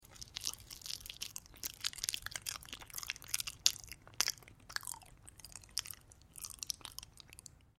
Звук очистки банана от кожуры